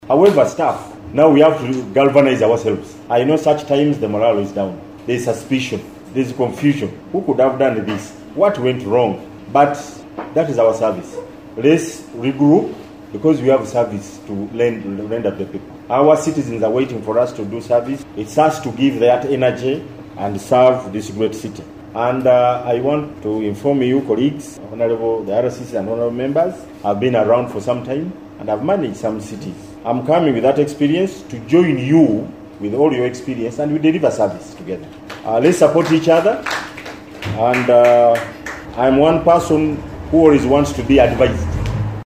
The newly appointed Resident City Commissioner (RCC) of Arua City, Charles Icokogor, emphasized the need to address rumors circulating within the city, citing it as a hindrance to effective service delivery. He expressed the commitment to correcting this issue for the betterment of the community.
11_09 - RCC ON ARUA CITY.mp3